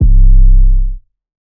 Aint Doin That 808.wav